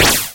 听起来像一颗模糊的子弹
这是使用杂牌的PC麦克风录制的; 使用Windows中的录音机直接录制到PC上。然后添加了后期效果。
标签： 射击 模糊 子弹
声道单声道